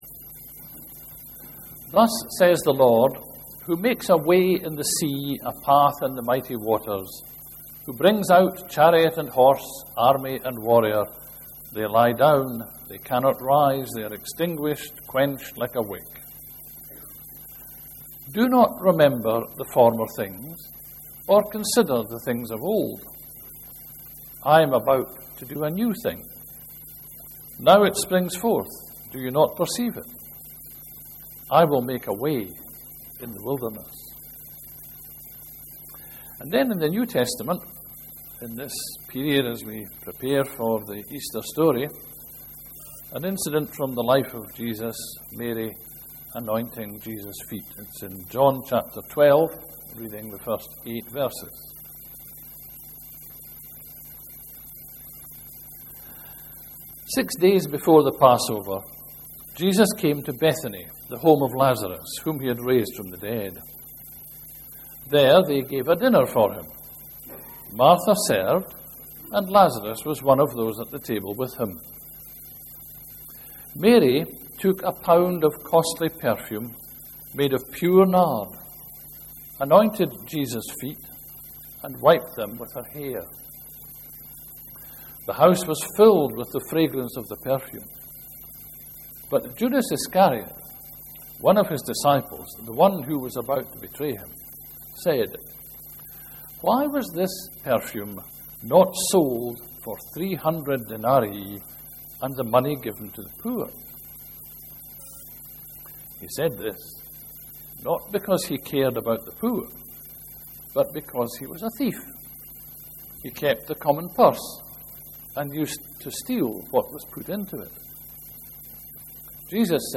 17/03/13 sermon – The importance of not living in the past (Isaiah 43:16-19 and John 12:1-8)